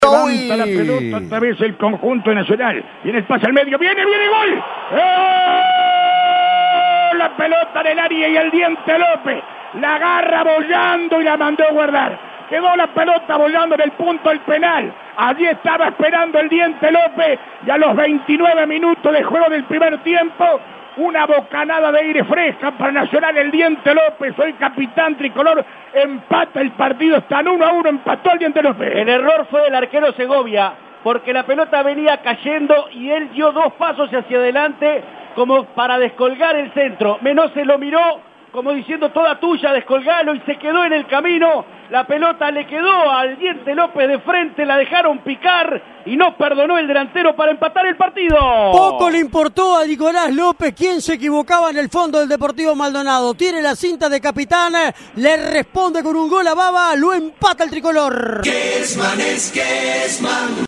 GOLES RELATADOS POR ALBERTO KESMAN